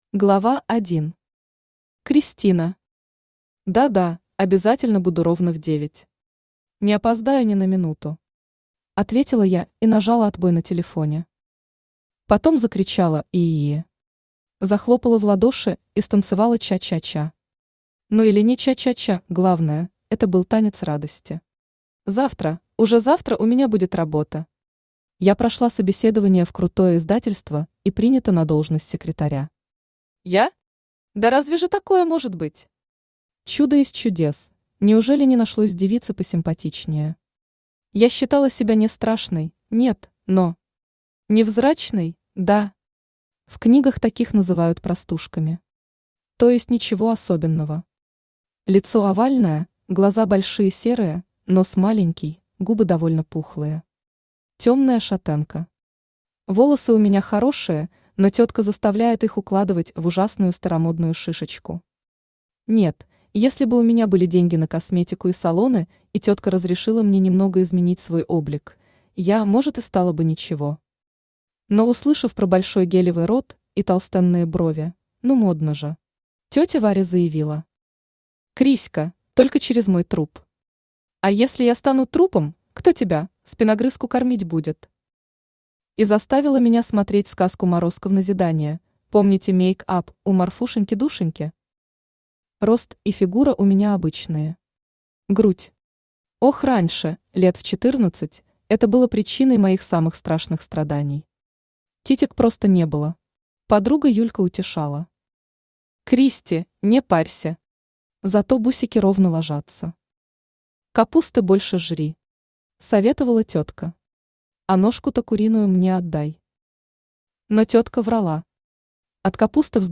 Aудиокнига Волшебник для серой мышки Автор Лилия Тимофеева Читает аудиокнигу Авточтец ЛитРес.